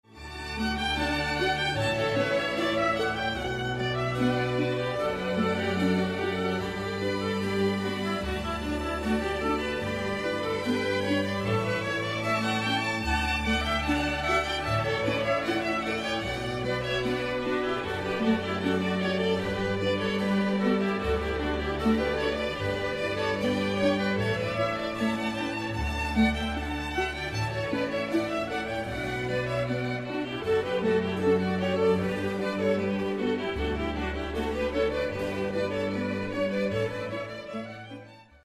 красивые
спокойные
без слов
скрипка
инструментальные
оркестр
симфония